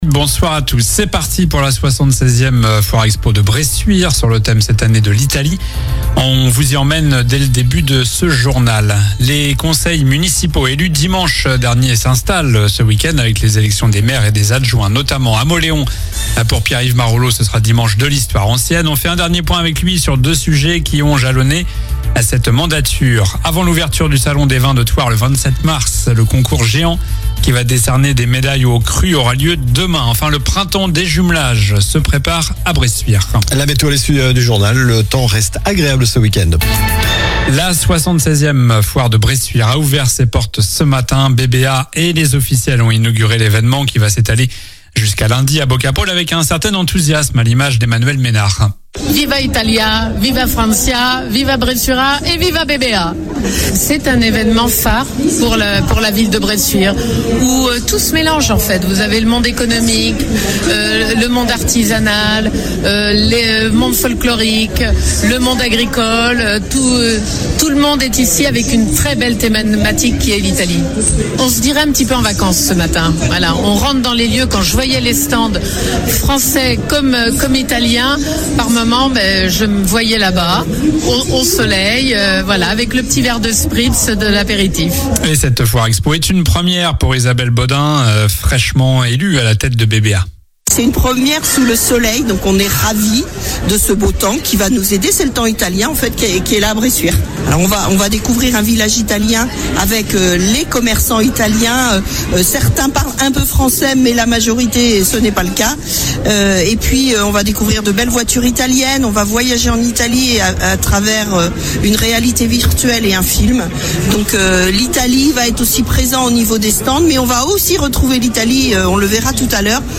Journal du vendredi 20 mars (soir)